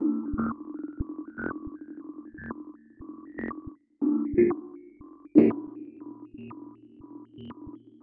Abstract Rhythm 29.wav